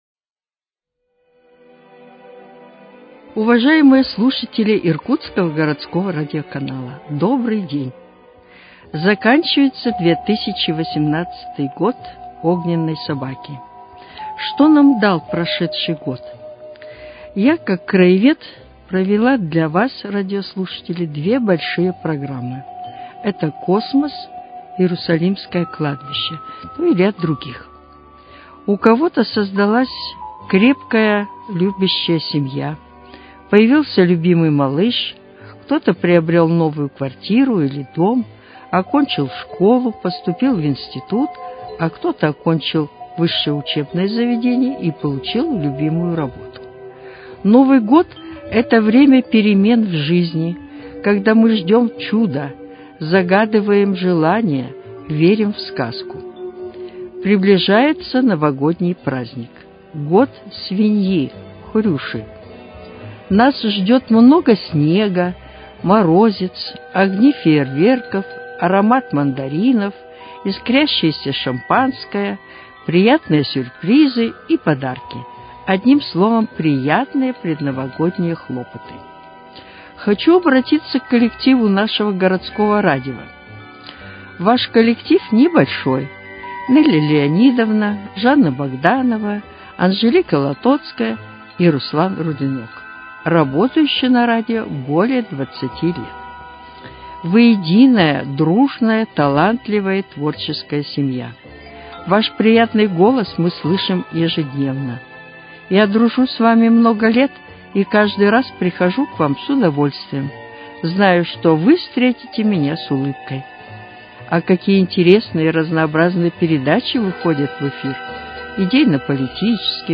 Из коллекции Иркутского радиоканала: Встречаем 2019 Новый год